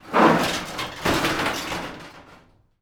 metal_sheet_impacts_09.wav